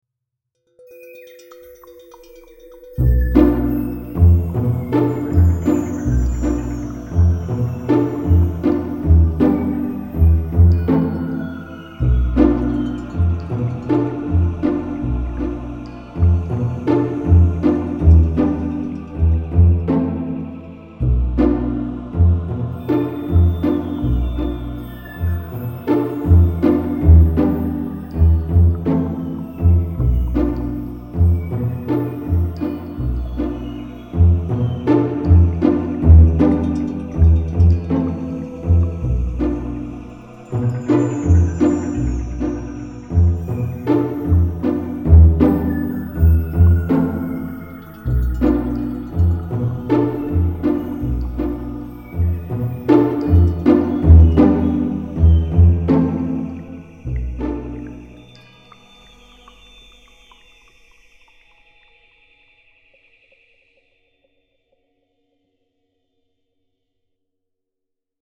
Tonalidade: si locrio; Compás 4/4